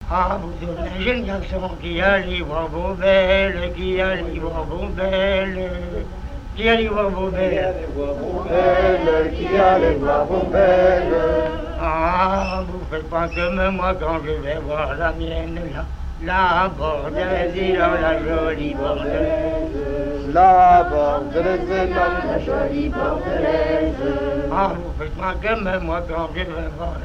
Genre laisse
Chansons traditionnelles interprétées par les personnes de la maison de retraite
Pièce musicale inédite